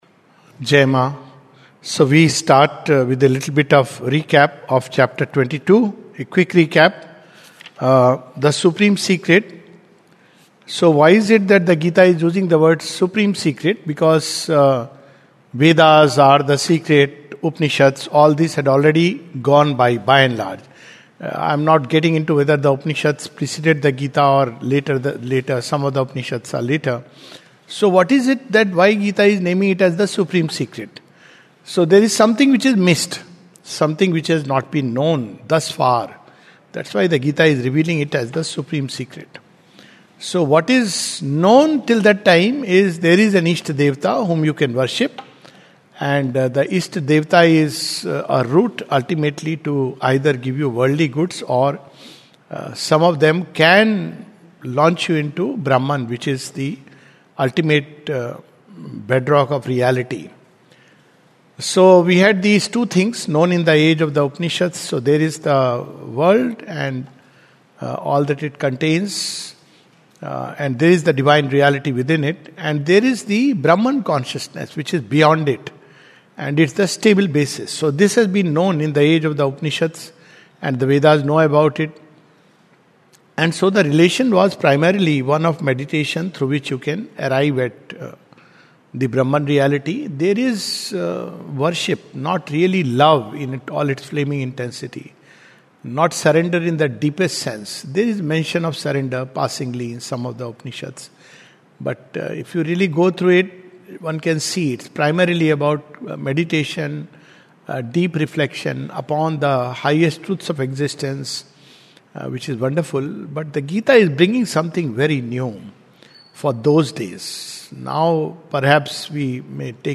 This is the second talk on the 22nd Chapter of the Second Series of "Essays on the Gita" by Sri Aurobindo (p. 540). Recorded on April 15, 2026 at Savitri Bhavan, Auroville.